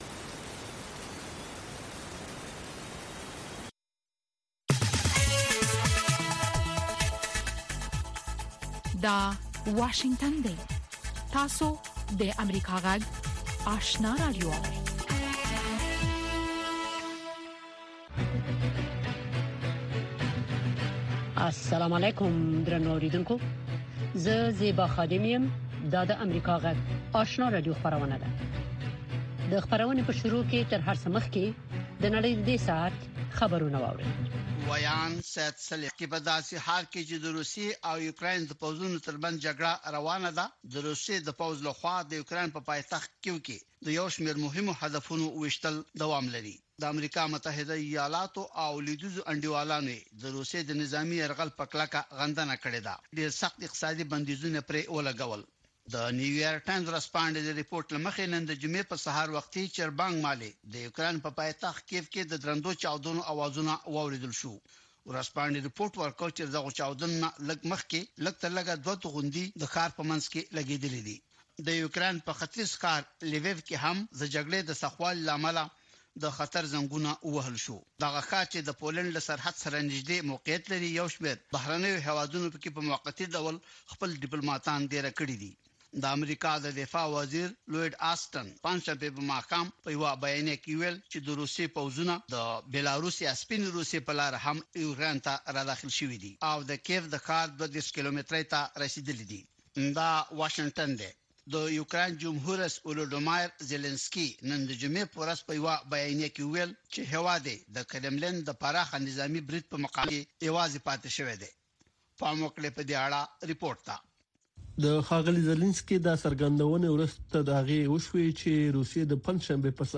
لومړنۍ ماښامنۍ خبري خپرونه